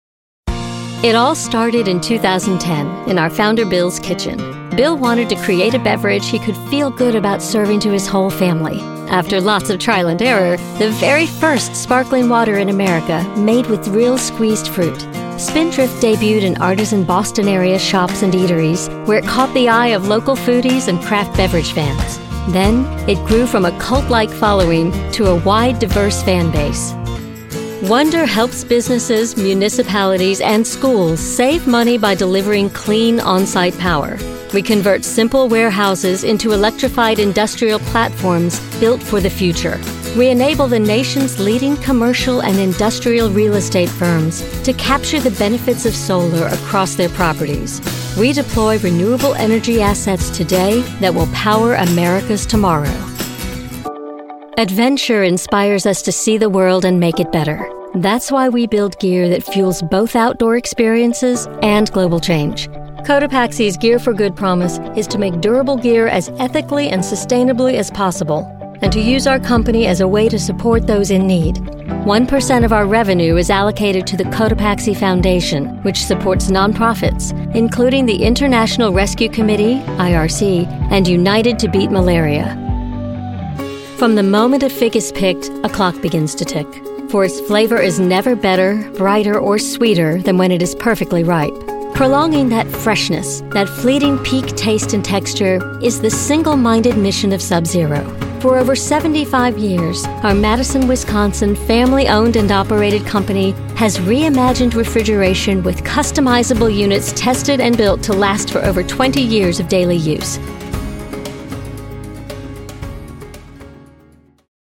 Natuurlijk, Toegankelijk, Veelzijdig, Vriendelijk, Zakelijk
Corporate